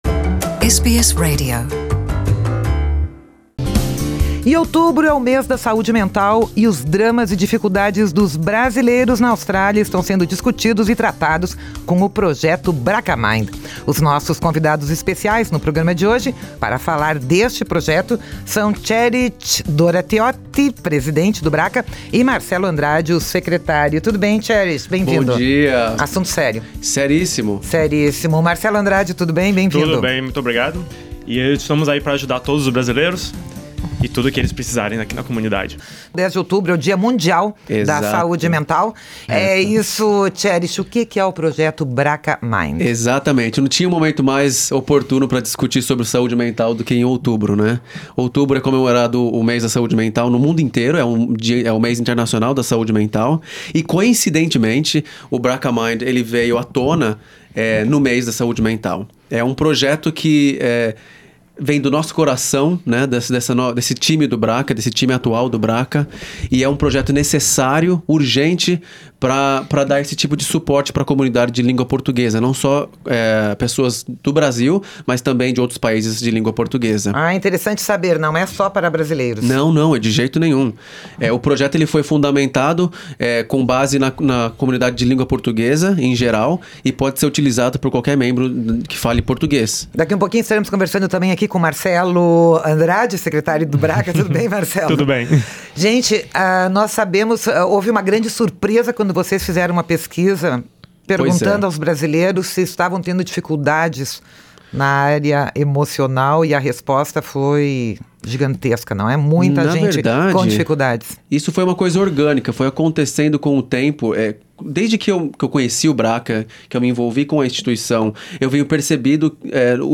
Conheça como o BRACCA Mind pode ajudá-lo com problemas emocionais. Nossos entrevistados são